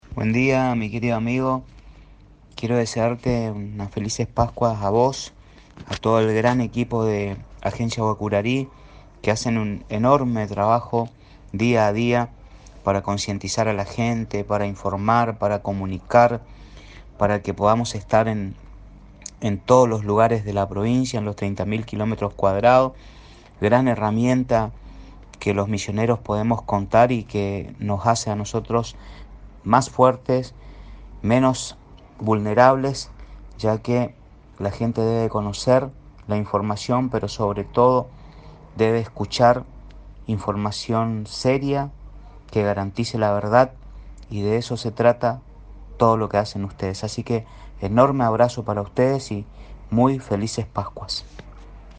Mensaje del Ministro Alarcón para la Agencia de Noticias Guacurari - Agencia de Noticias Guacurari
El Ministro de Salud Oscar Alarcón envío sus salutaciones a todos los integrantes de la agencia de Noticias Guacurarí deseando una felices pascuas …